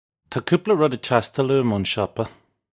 Tah koopla rud ig chass-tile oo-im own shuppa. (U)
This is an approximate phonetic pronunciation of the phrase.